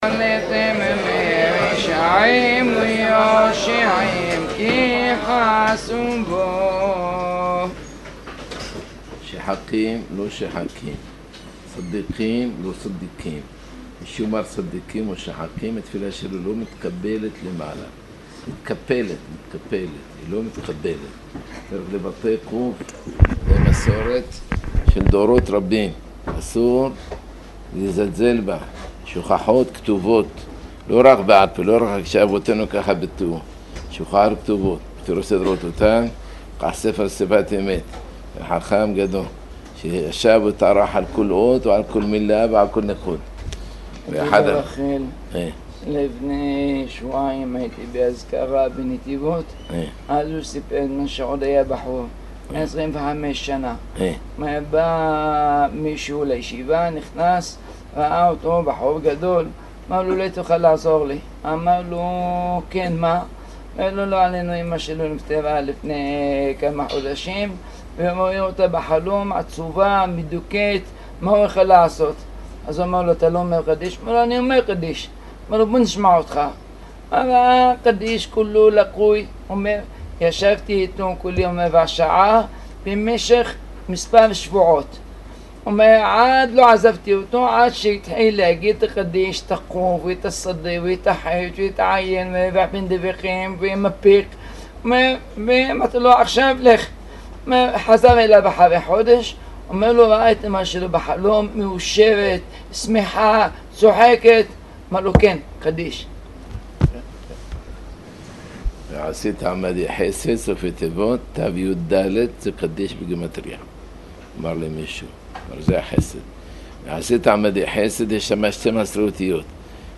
שעור מוסר והלכה הנמסר לישיבה קטנה, בין הנושאים: